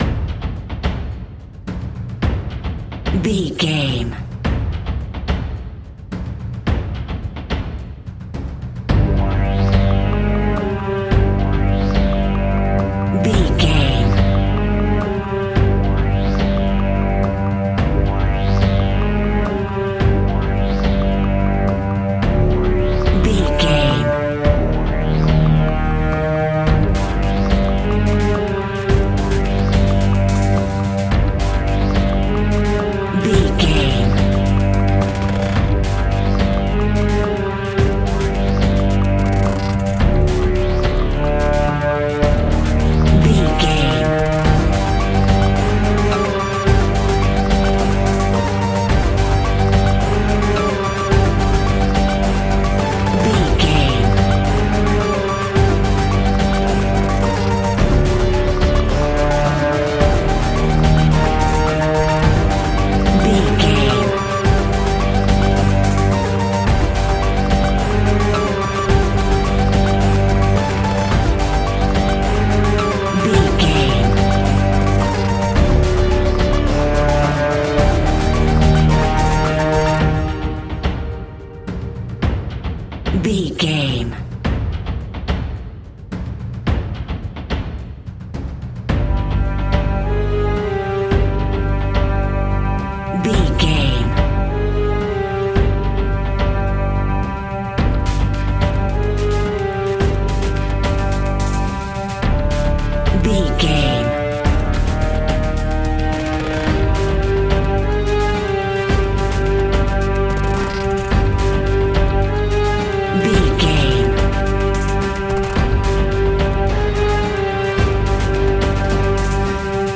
Ionian/Major
dramatic
epic
powerful
strings
percussion
synthesiser
brass
violin
cello
double bass